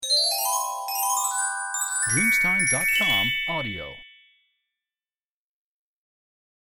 Carillon lungo di scintillio di aumento